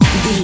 Uplifting
Aeolian/Minor
Fast
drum machine
synthesiser
electric piano
conga